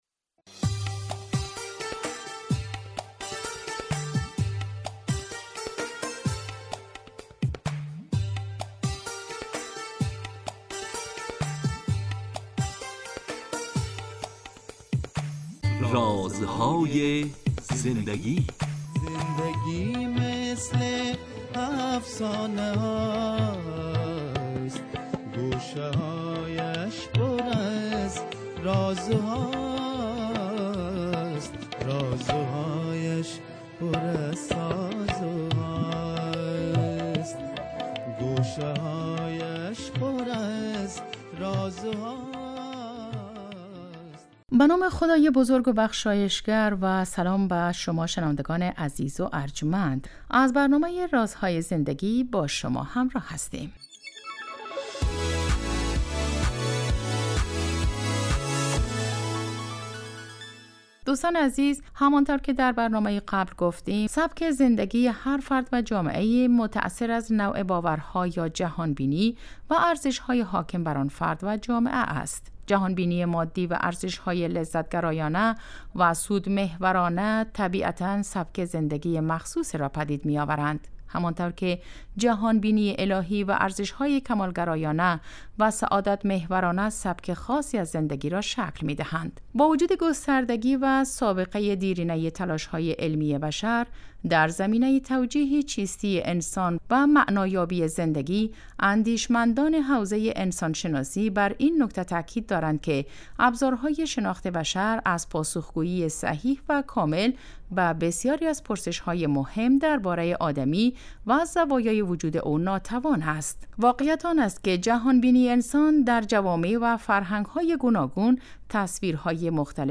این برنامه به مدت 15 دقیقه هر روز ساعت 11:35 به وقت افغانستان از رادیو دری پخش می شود .